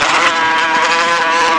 Hornet Sound Effect
Download a high-quality hornet sound effect.
hornet-1.mp3